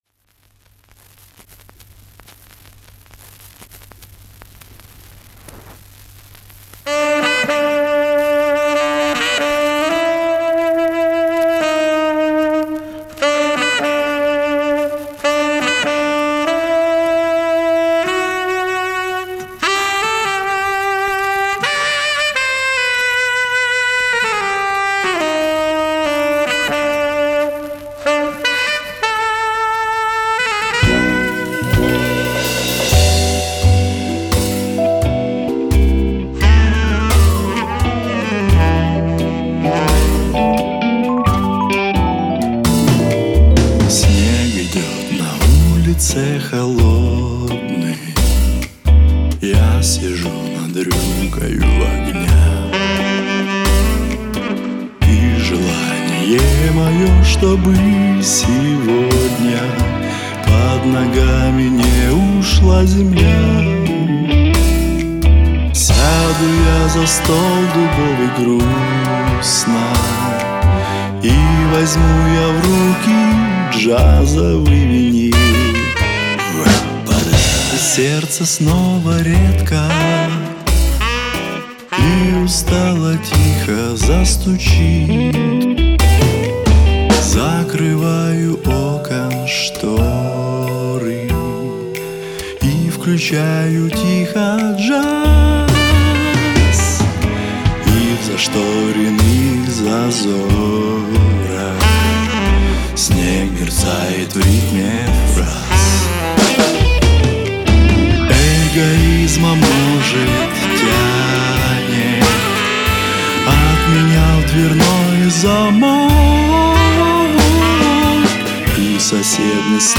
Исполняет поп, джаз.